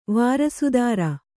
♪ vārasudāra